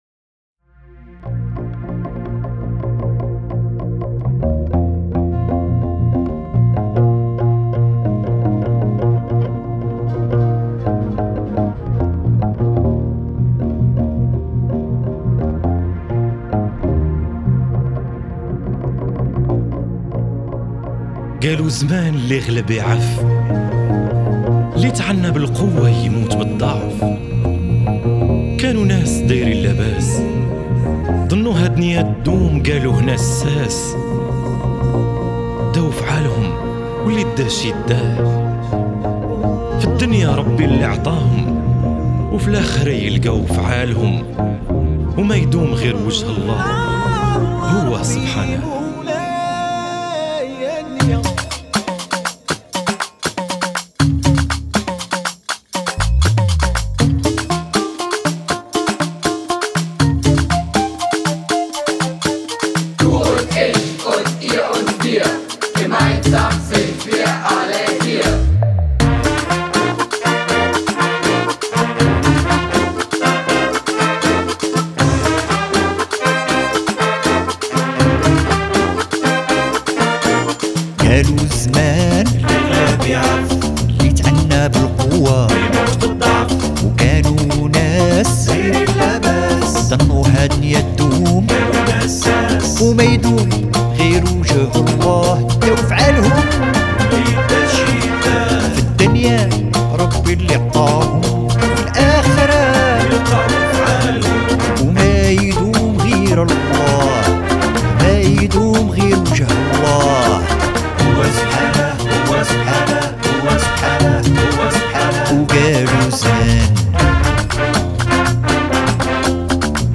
Die Fotos entstanden im Jamin Tonstudio.